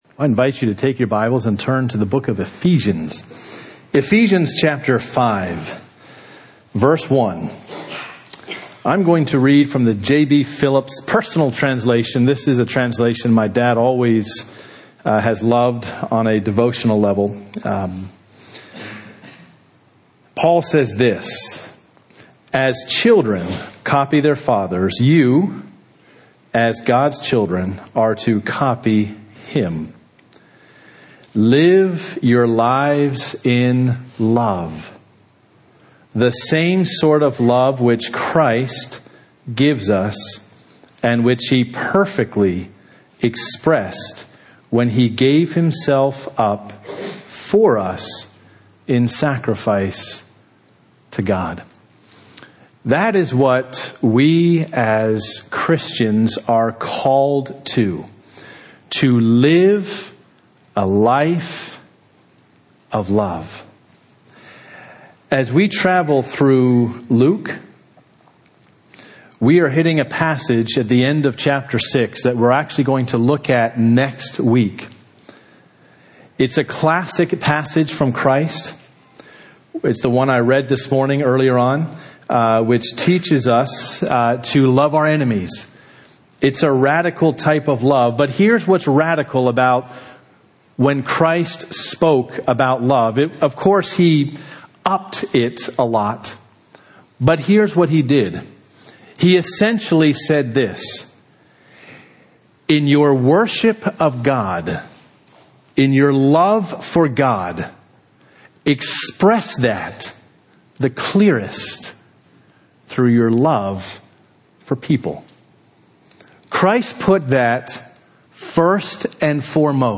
This Sunday will be a refresher message on what love should look like between husband and wife.